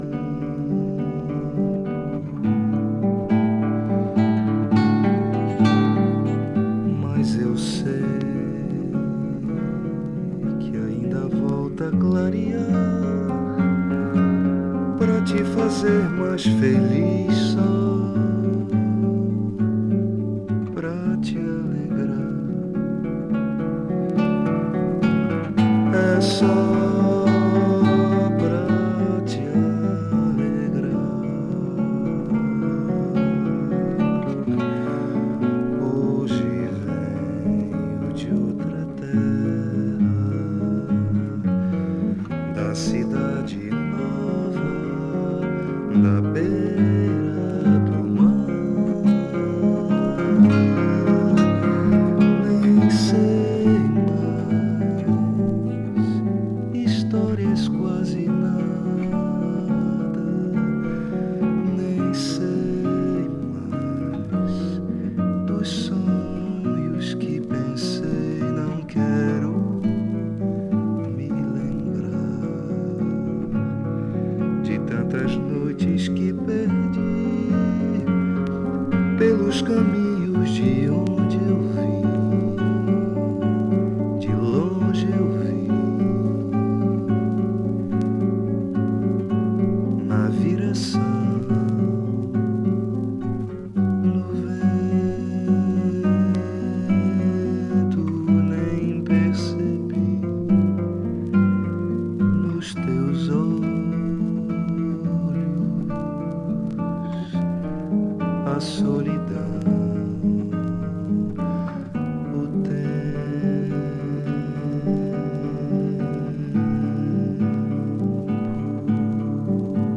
percussionist
drummer